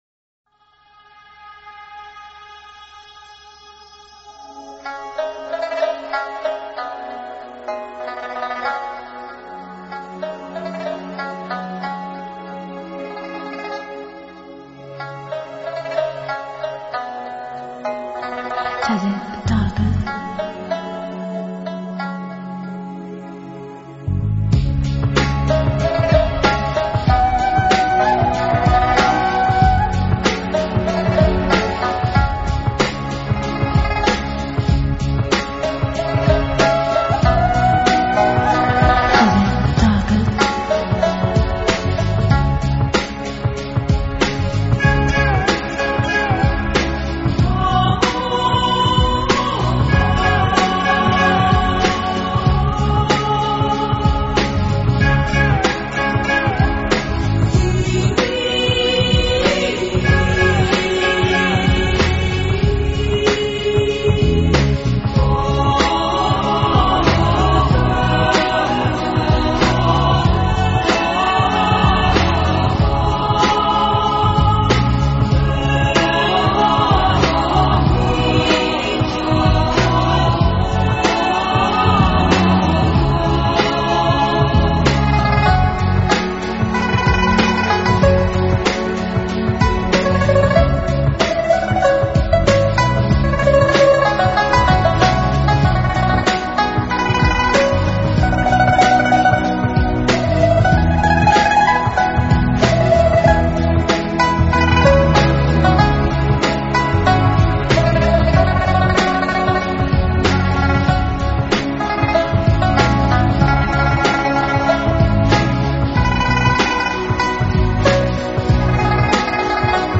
发烧级的录音水准，令发烧乐迷，留下深刻印象。